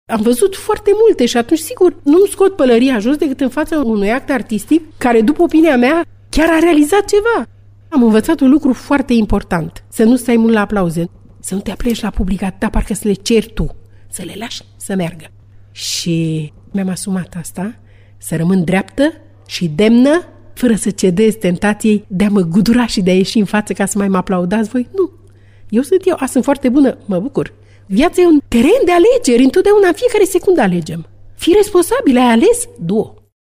Cristina Stamate într-un interviu acordat postului nostru de radio.